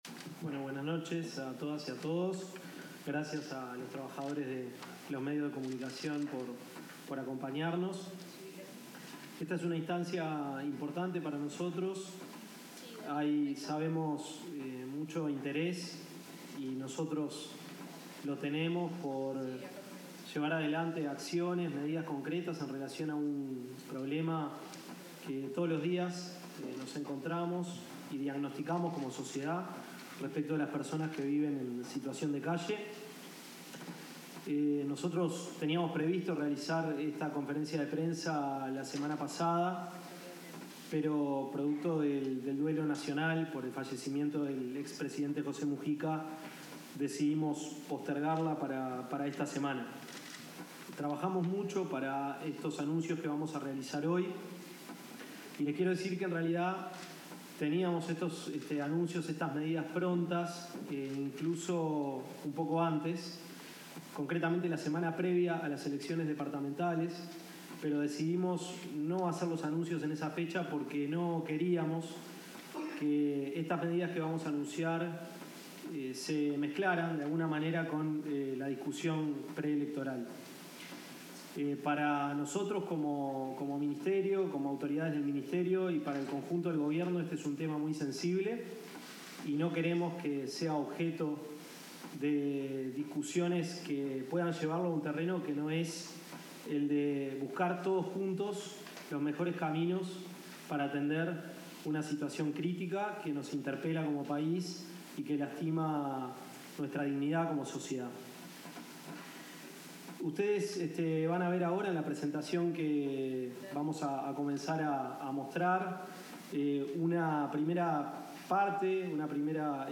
Palabras de las autoridades del Ministerio de Desarrollo Social
Durante la presentación de iniciativas para abordar la realidad de las personas en situación de calle, efectuada este lunes 19 en la sede del